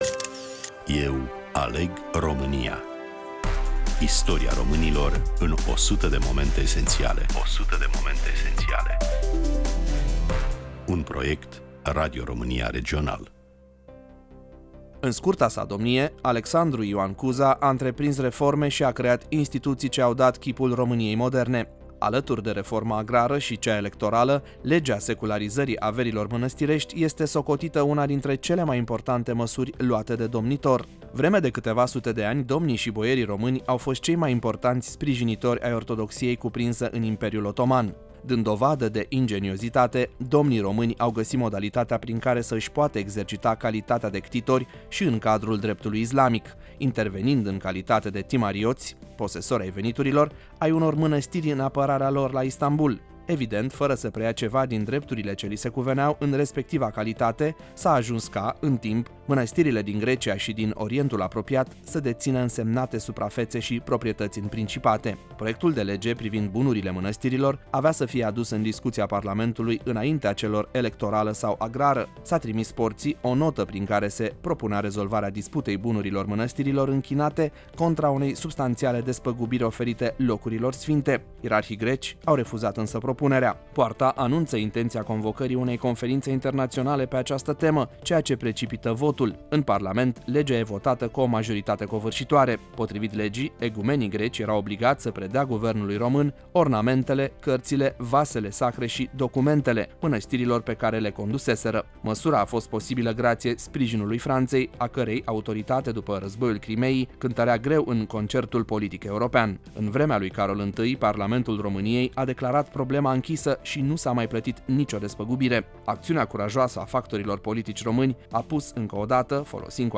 Prezentator / voice over